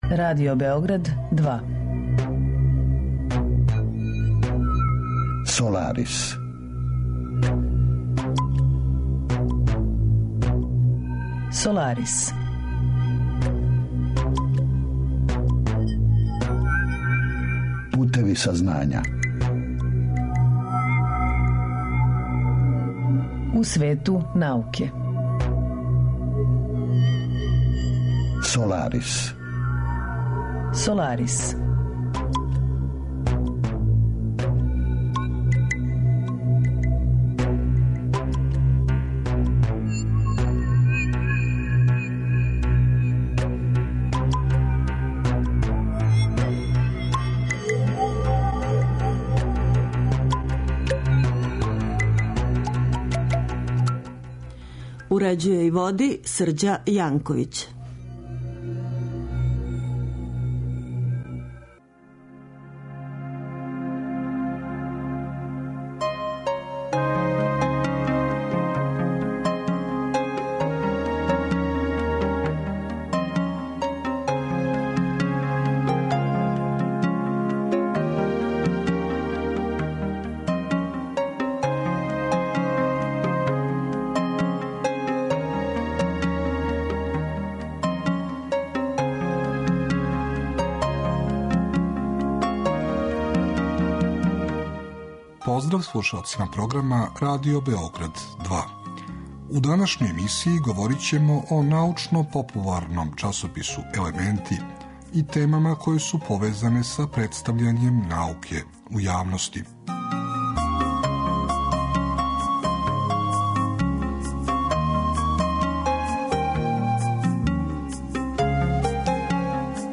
Емисија је посвећена темама из области природних наука које обухватају како базична, тако и примењена научна истраживања, са посебним освртом на научни метод, историју и филозофију науке, као и различите аспекте односа науке и друштва.